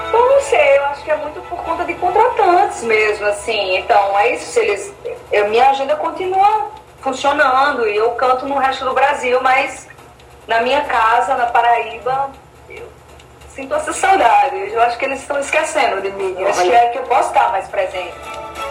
Durante entrevista ao programa Frente a Frente, da TV Arapuan, nesta segunda-feira (04/12), disse que é preciso haver um equilíbrio no número de atrações que são contratadas, ressaltando que não é contra a participação das grandes atrações.